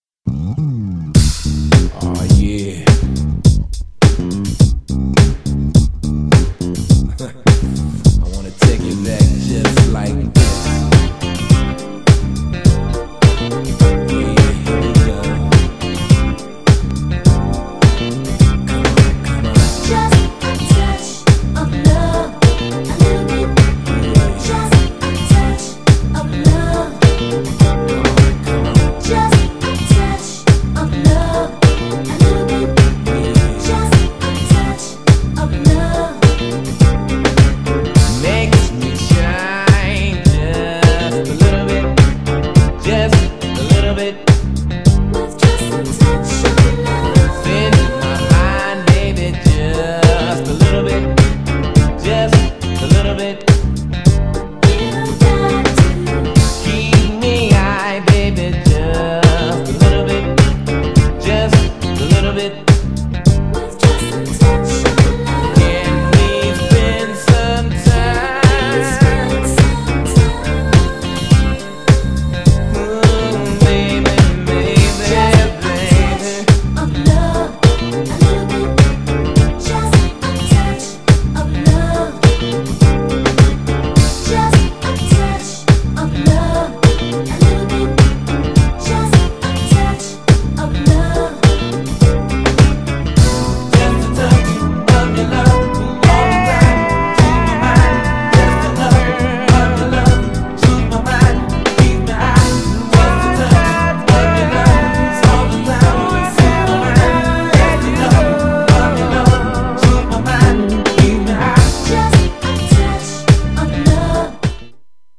GENRE R&B
BPM 56〜60BPM
スロー
メロウ # 男性VOCAL_R&B